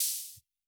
Index of /musicradar/retro-drum-machine-samples/Drums Hits/WEM Copicat
RDM_Copicat_MT40-OpHat02.wav